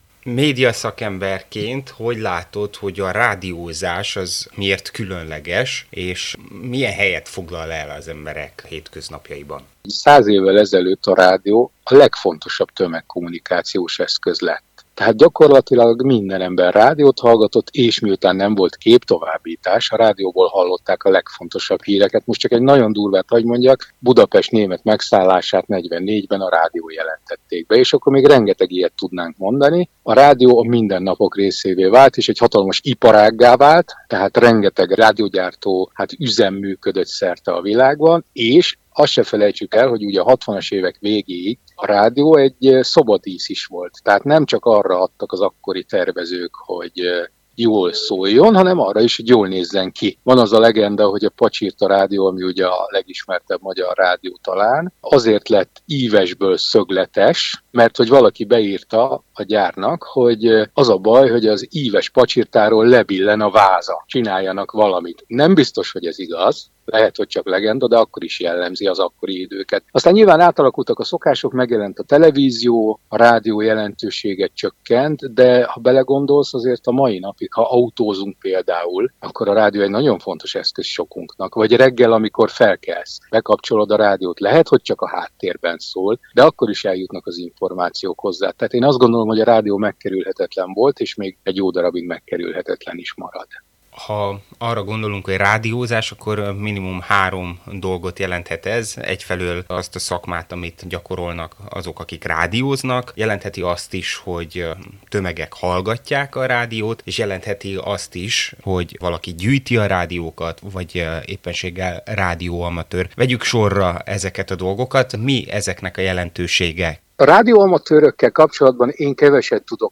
A rádió évtizedekig megkerülhetetlen volt, és egy jó ideig megkerülhetetlen is marad – vélekedett a Marosvásárhelyi Rádió által megkérdezett médiaszakember.